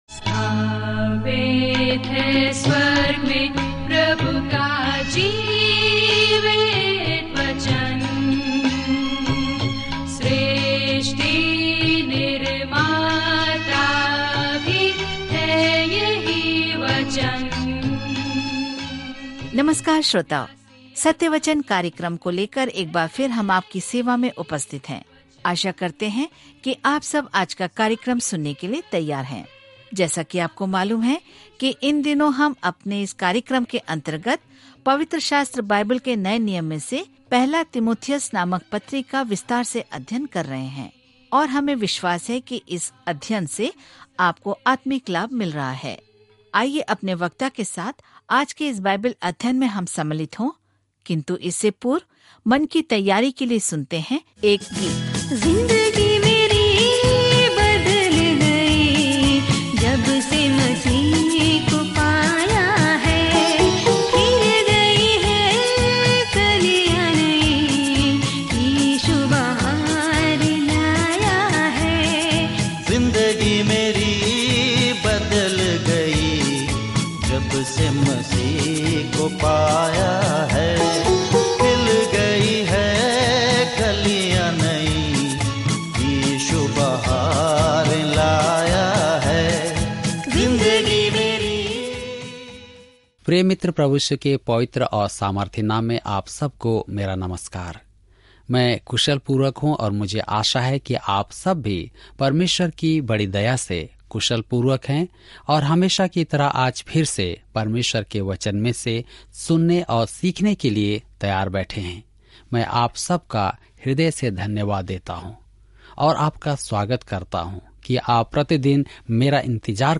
पवित्र शास्त्र 1 तीमुथियुस 4:16 1 तीमुथियुस 5:1-12 दिन 10 यह योजना प्रारंभ कीजिए दिन 12 इस योजना के बारें में तीमुथियुस को लिखा पहला पत्र व्यावहारिक संकेत प्रदान करता है कि किसी को सुसमाचार द्वारा बदल दिया गया है - जो कि ईश्वरत्व के सच्चे लक्षण हैं। 1 तीमुथियुस के माध्यम से दैनिक यात्रा करें क्योंकि आप ऑडियो अध्ययन सुनते हैं और भगवान के वचन से चुनिंदा छंद पढ़ते हैं।